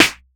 • Steel Snare Drum Sample G Key 21.wav
Royality free snare drum tuned to the G note.
steel-snare-drum-sample-g-key-21-9f5.wav